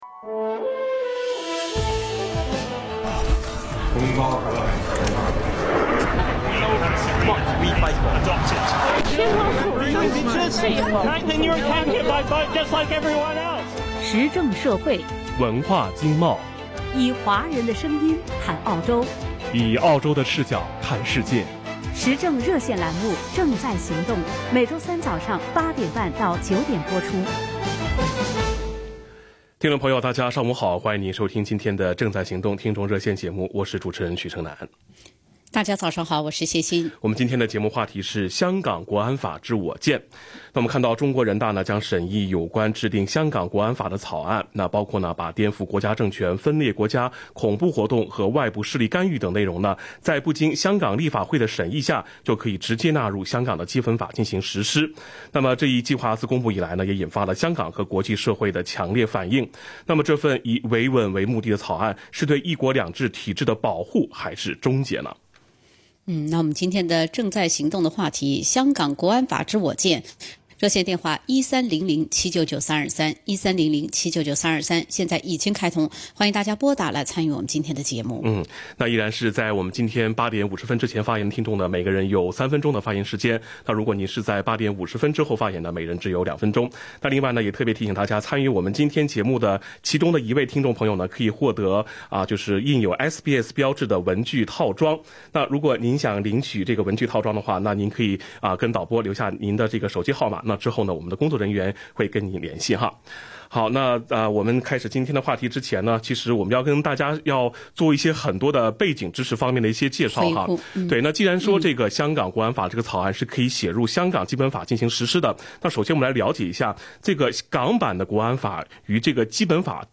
action_talkback_may_27.mp3